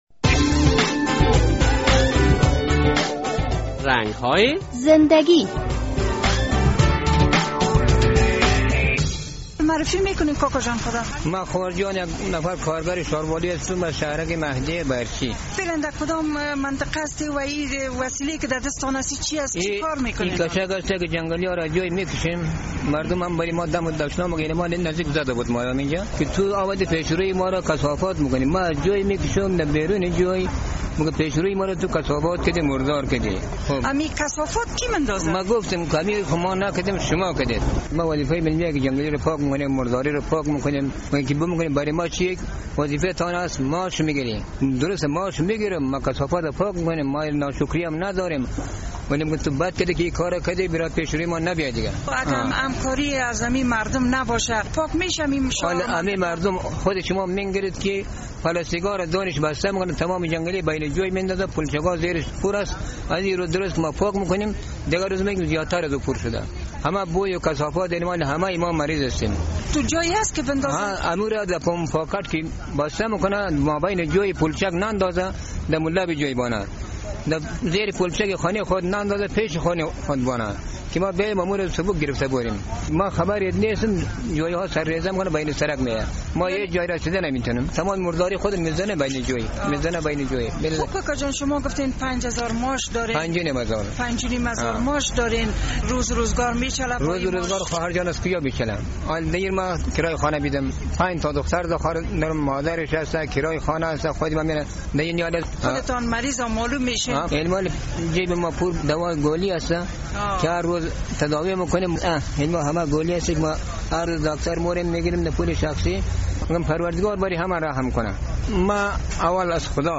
مصاحبه با یک تن از کارگران شاروالی که خیلی از مردم منطقۀ پروان سوم شکایت دارد و می‌گوید، این مردم نباید ذباله های شان را در میان جویچه ها باندازند، آنان کثافات و ذباله های شانرا در لب سرک بگذارند تا آنان آمده و جمع کنند.